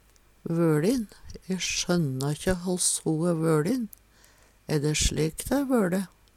Sjå òg litenvøLin (Veggli) kLeinvøLin (Veggli) vakkLevøLin (Veggli) smaLvøLin (Veggli) breivøLin (Veggli) Høyr på uttala